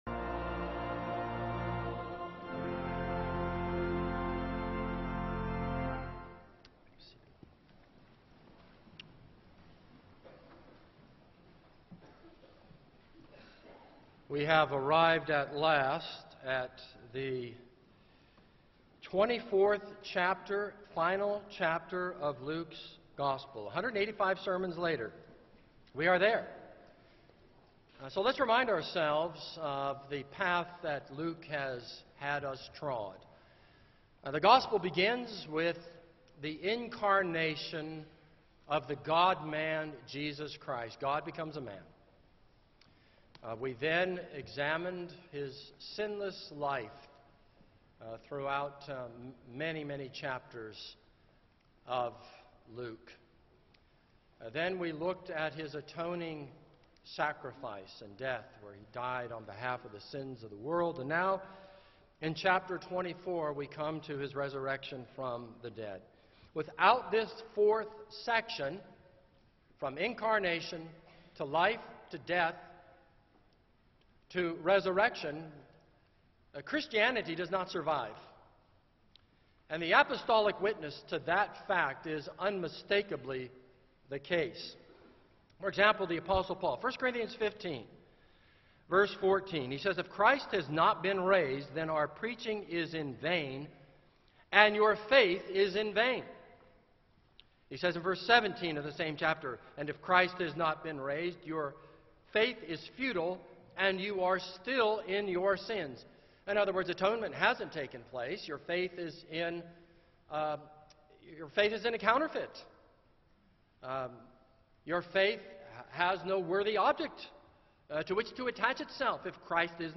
This is a sermon on Luke 24:1-12.